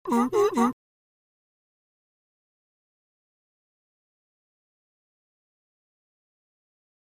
Motor Pump; Piston Pump, Quick On / Off With Bellow Honk